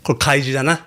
korekai shi dana Meme Sound Effect
This sound is perfect for adding humor, surprise, or dramatic timing to your content.